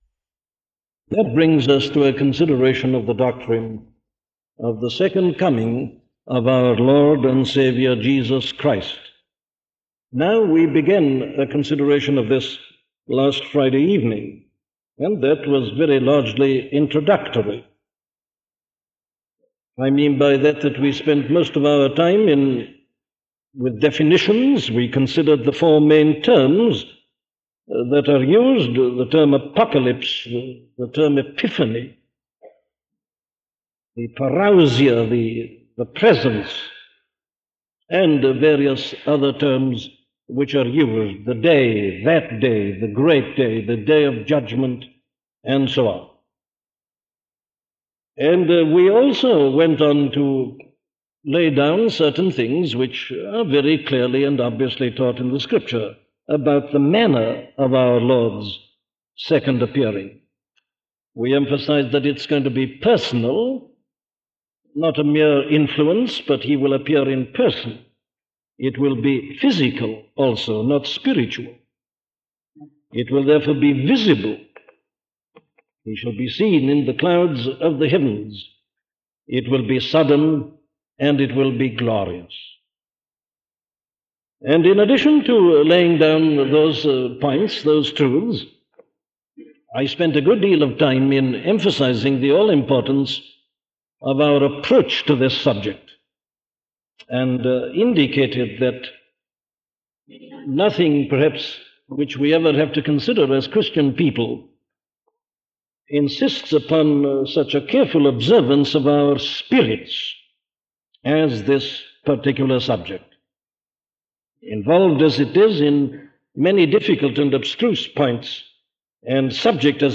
The Time of His Coming - the Signs - a sermon from Dr. Martyn Lloyd Jones